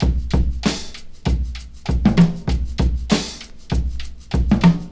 • 97 Bpm Drum Groove B Key.wav
Free breakbeat sample - kick tuned to the B note. Loudest frequency: 1085Hz
97-bpm-drum-groove-b-key-KGO.wav